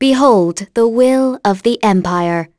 voices / heroes / en
Glenwys-Vox_Skill5.wav